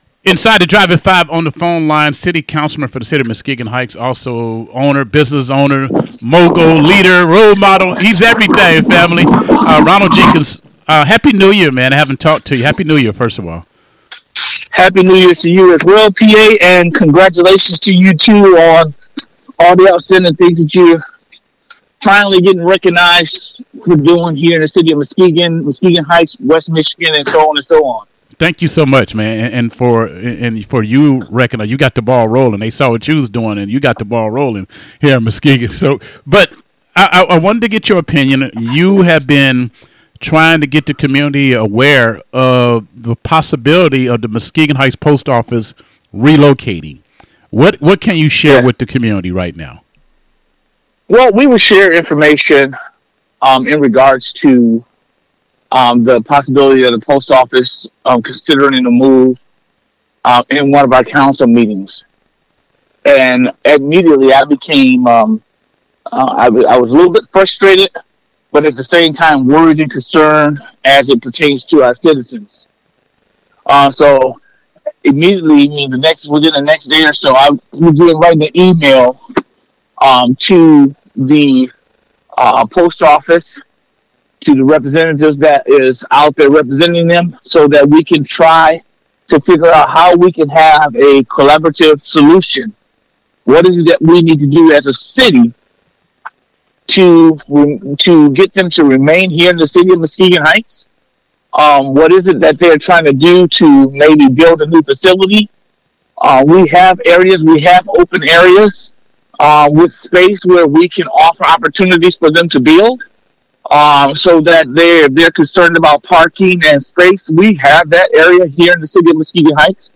Interview with City of Muskegon Heights Council Member Ronald Jenkins